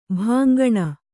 ♪ bhāngaṇa